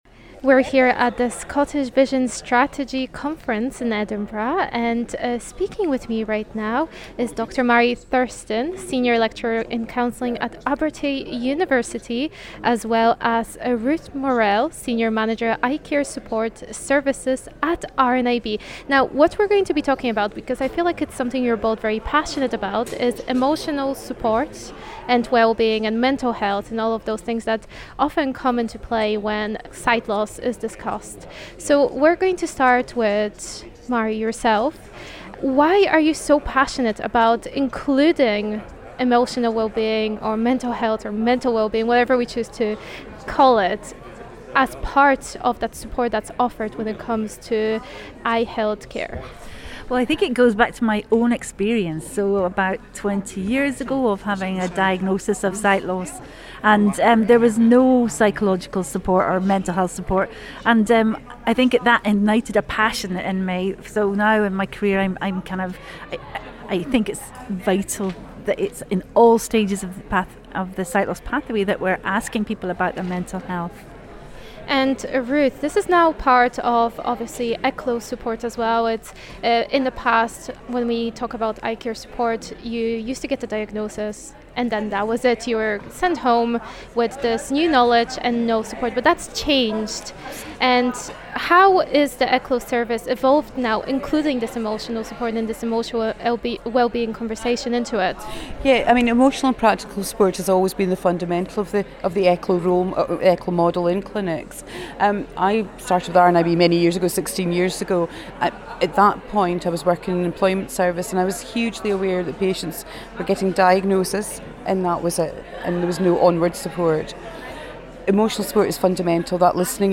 Scottish Vision Strategy Conference was organised by leading sight loss charities RNIB Scotland and Sight Scotland and took place in Edinburgh on the 8th of March 2023.